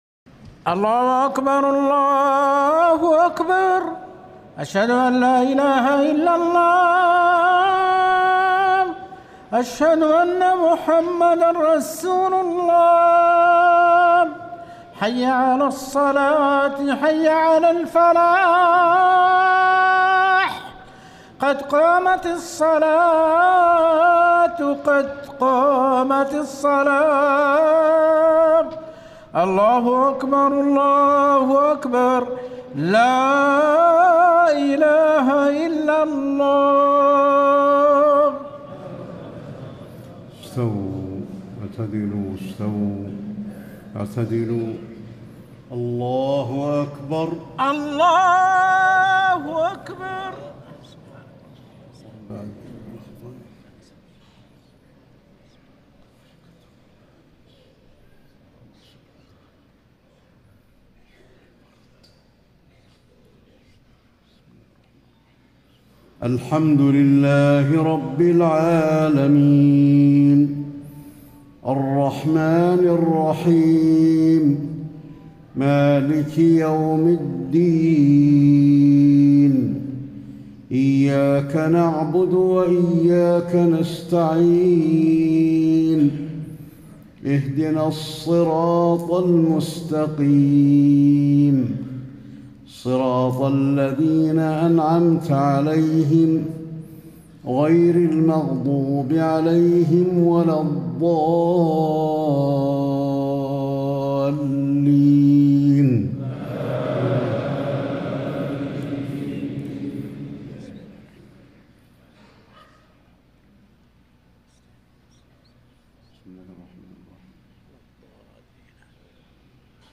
صلاة المغرب 5-1435 سورتي الماعون و الكوثر > 1435 🕌 > الفروض - تلاوات الحرمين